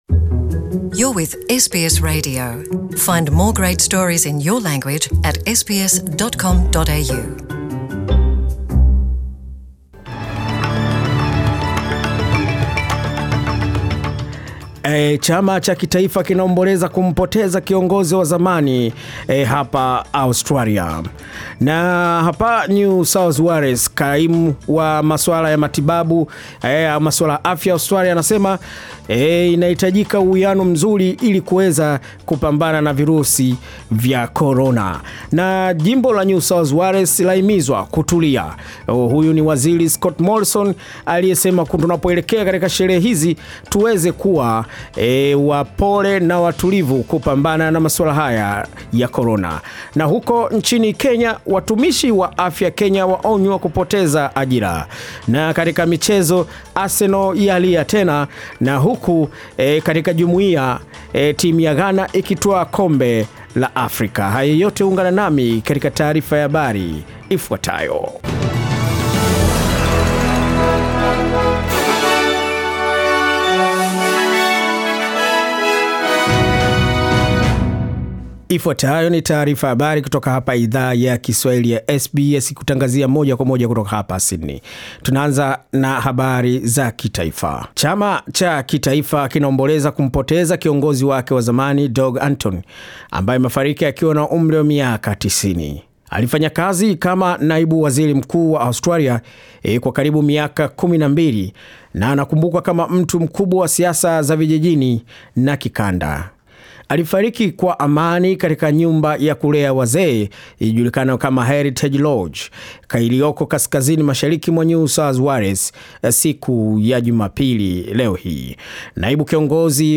Taarifa ya habari 20 Disemba 2020